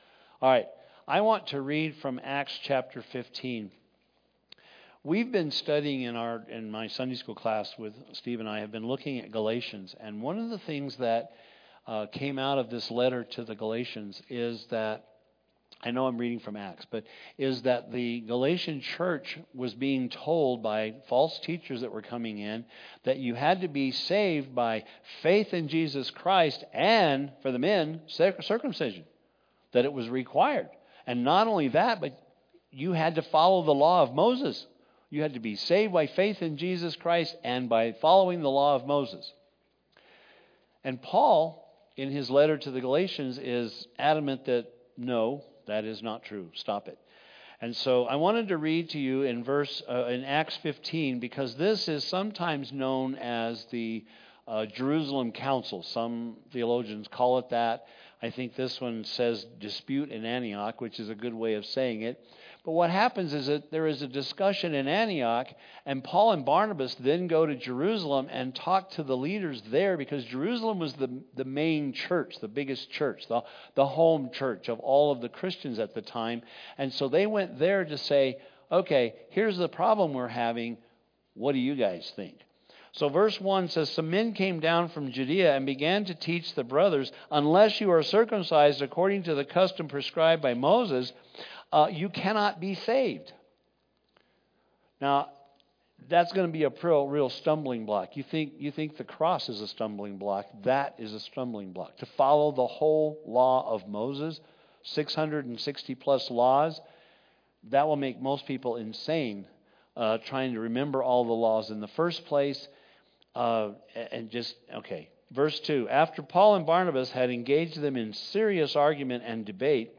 Have you heard the Good News of God's provisions for you and how He deals justly with your enemies? In today's sermon